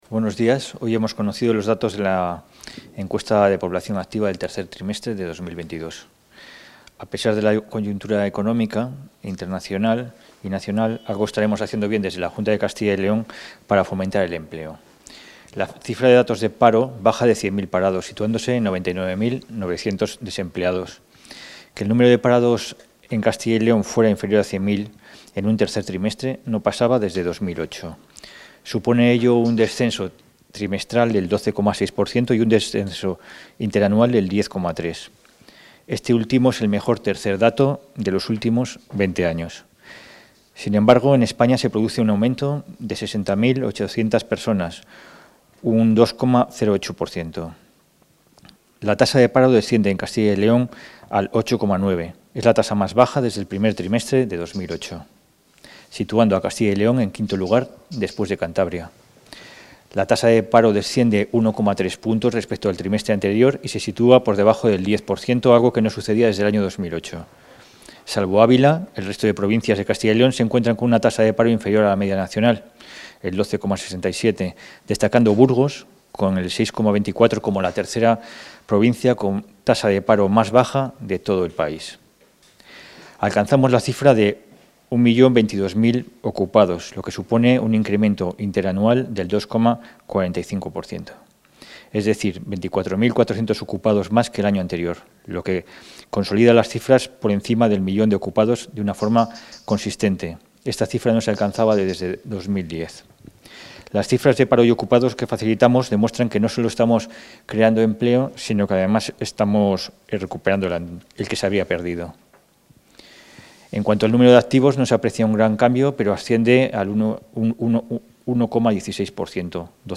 El secretario general de la Consejería de Industria, Comercio y Empleo, Alberto Díaz, ha valorado hoy los datos de la EPA del tercer trimestre del año 2022.